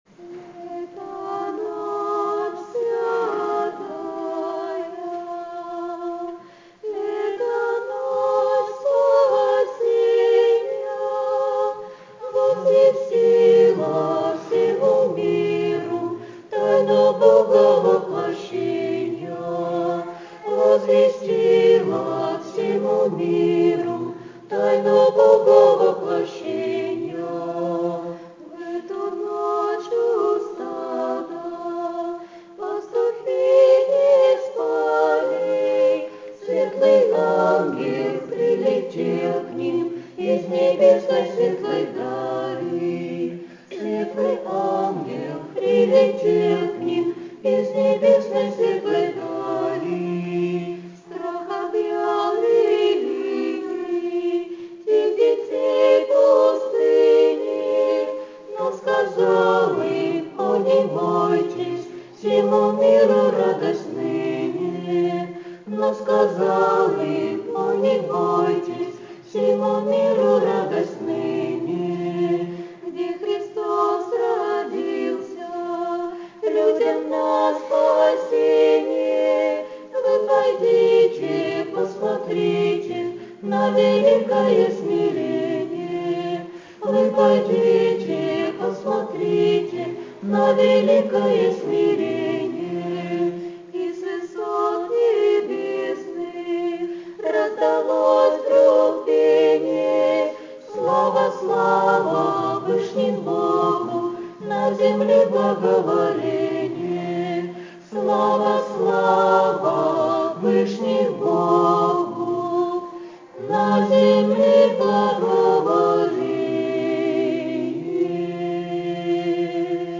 Колядка:
колядка.mp3